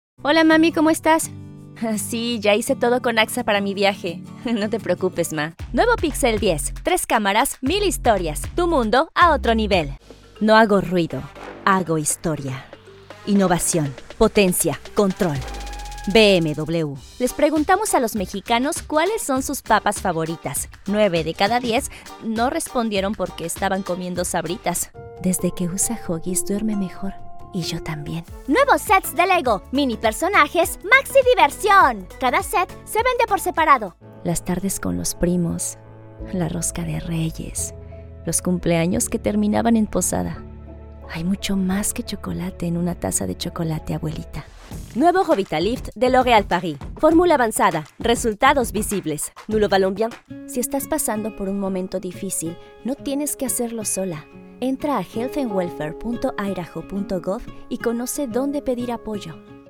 Spanisch (Lateinamerikanisch)
Junge, Natürlich, Freundlich, Sanft, Corporate
Kommerziell
Her accent is known to be “neutral” or “international”.